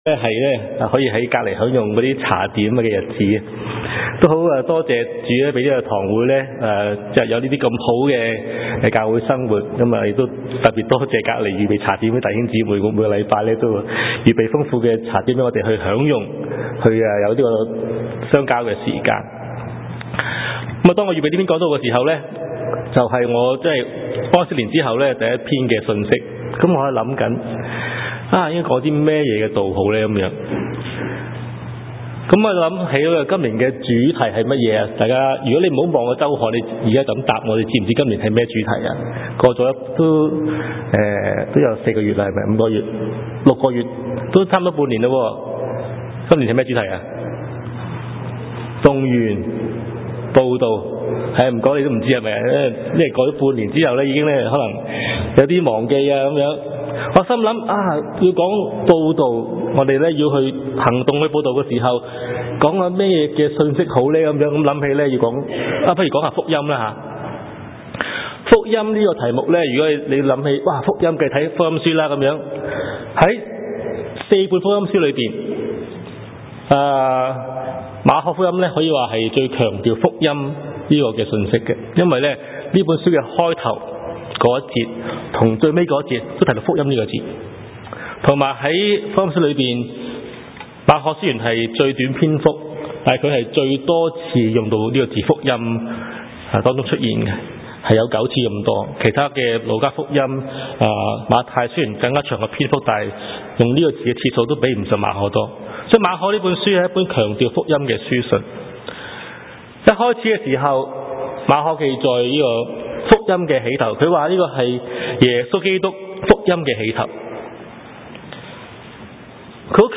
牛頓粵語崇拜 , 講道